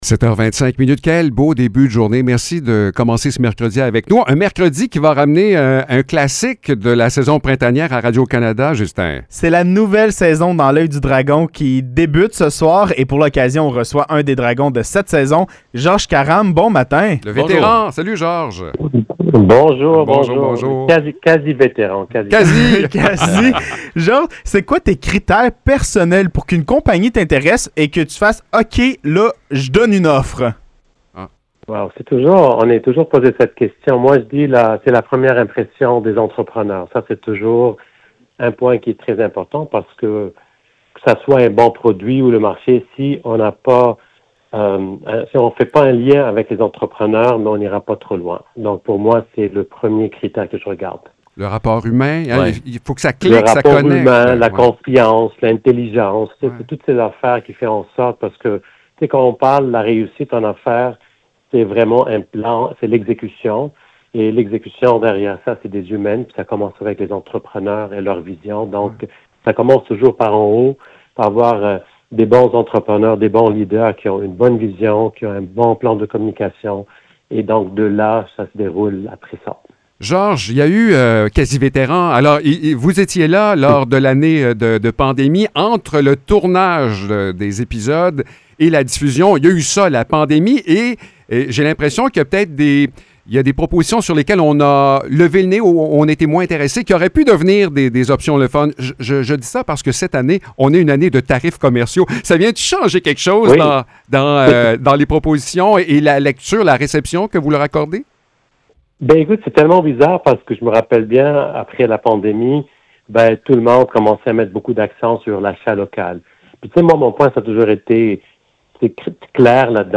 Entrevue avec Georges Karam